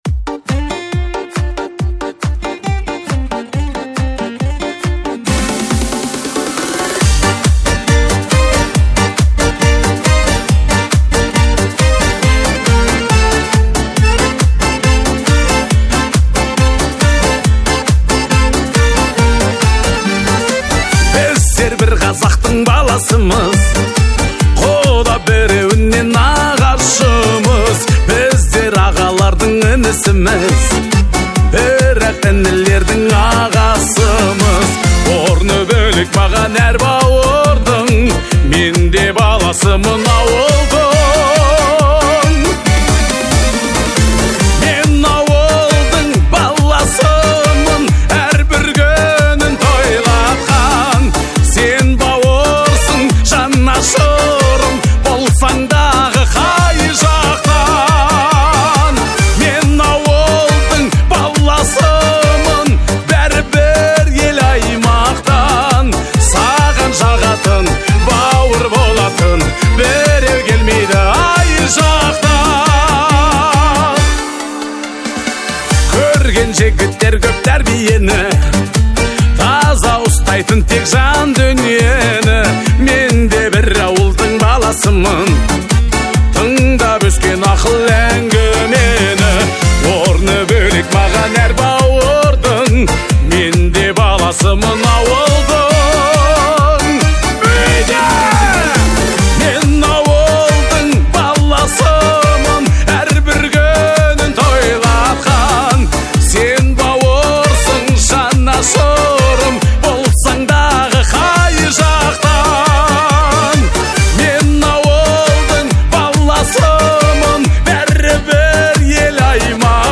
• Категория: Казахские песни